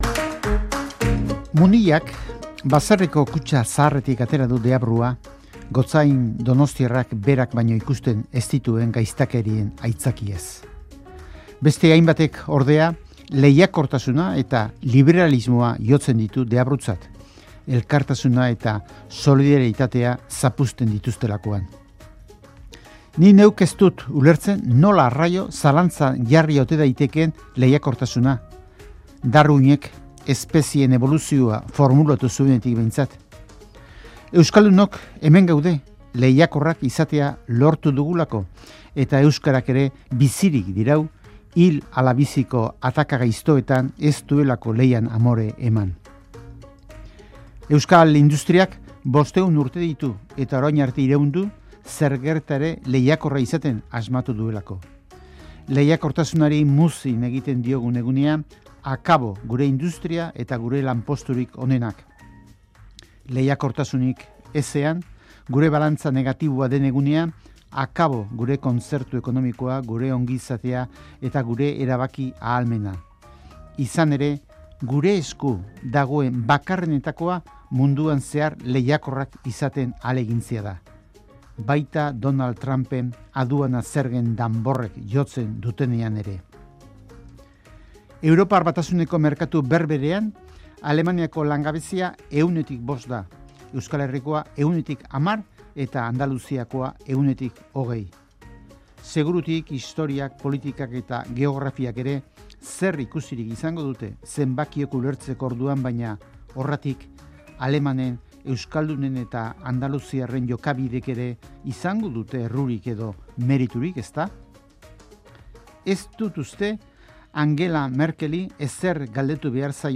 iritzi-jardunean